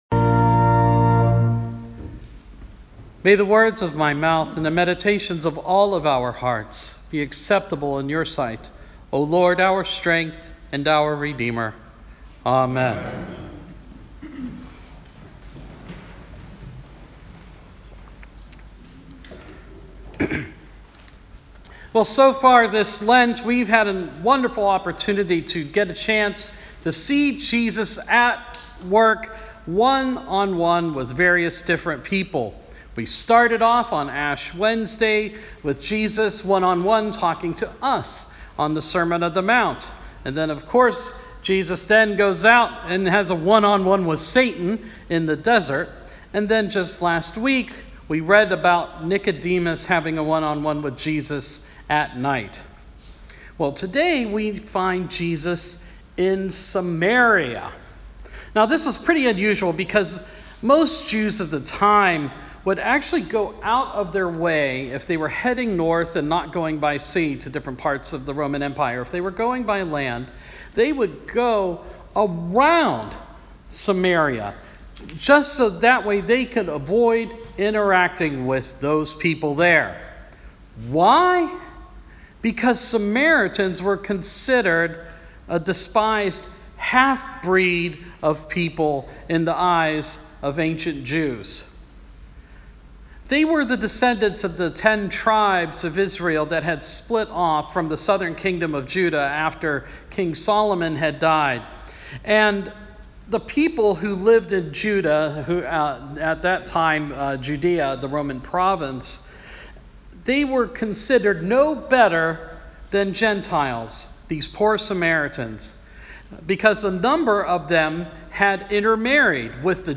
Amen.